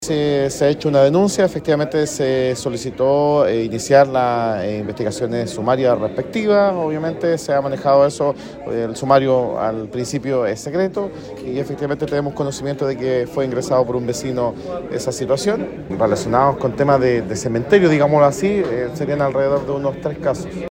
Según expuso el alcalde de la comuna, Helmuth Martínez, de momento se han investigado tres casos, y se lleva adelante una investigación reservada.